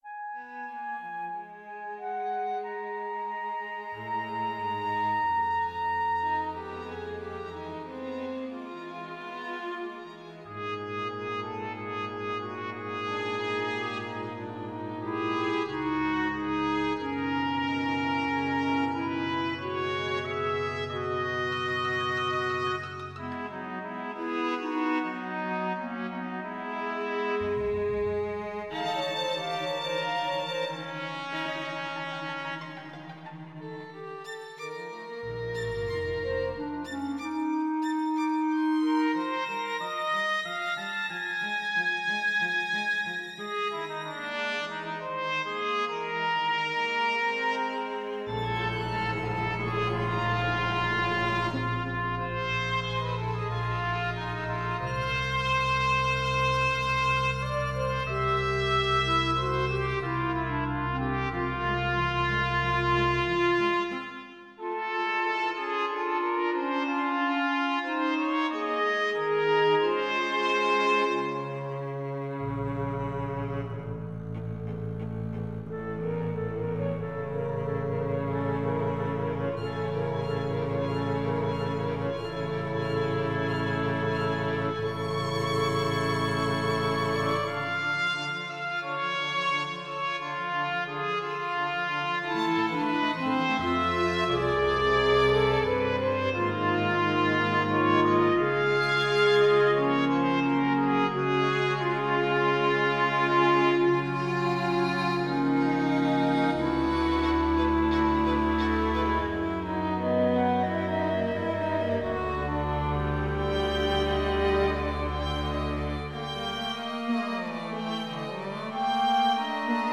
I got inspired a few weeks ago and have just finished a short chamber piece for trumpet, clarinet, violin, viola, cello, and double-bass.
chamber.mp3